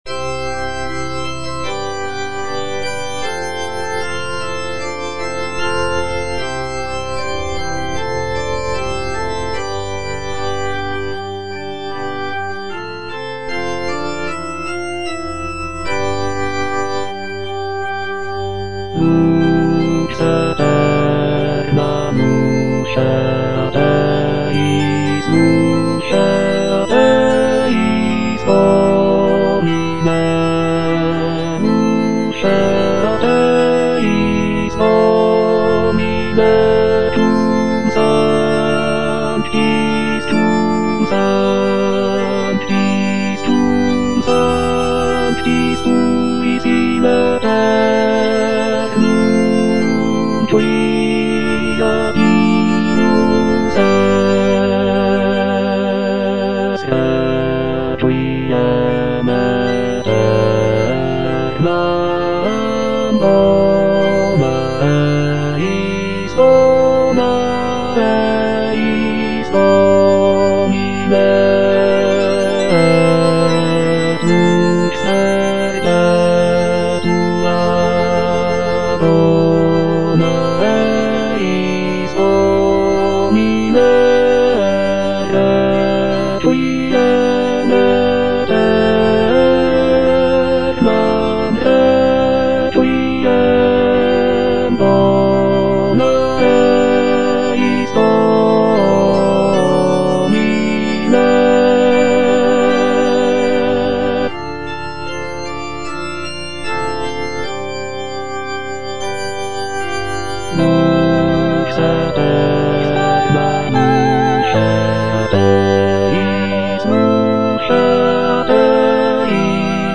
(bass I) (Emphasised voice and other voices)